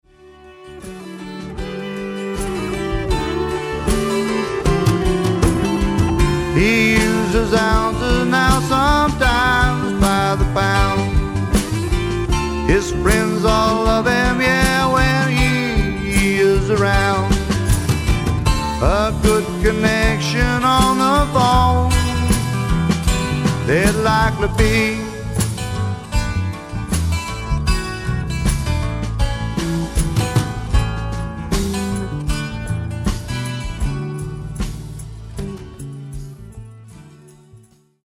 ヴォーカル、12弦ギター
ベース
パーカッション
ピアノ